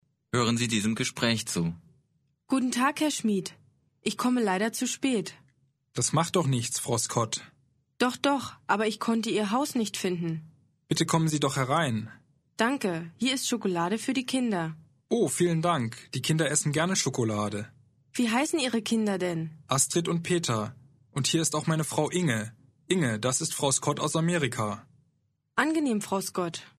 Аудио курс для самостоятельного изучения немецкого языка.